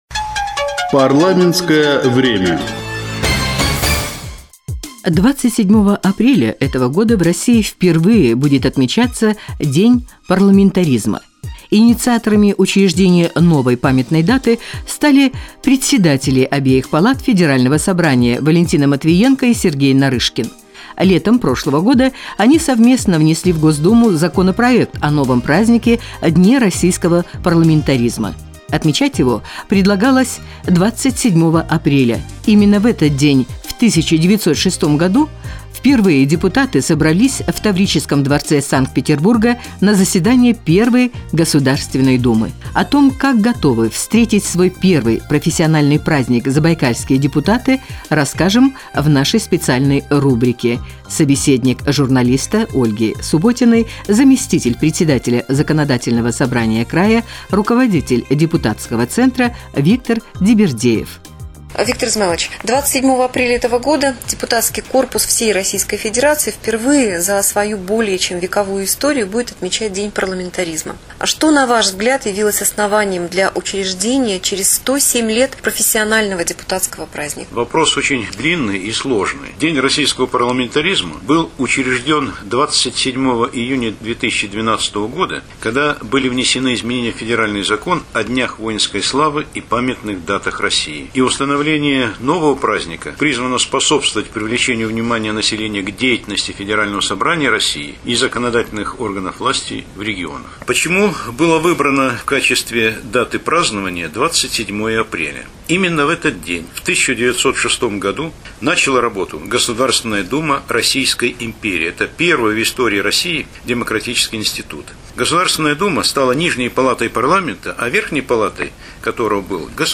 Информационный сюжет "День парламентаризма" (Радио России - Чита)
Интервью с заместителем председателя Законодательного Собрания В.И.Дибирдеевым о первом профессиональном празднике депутатов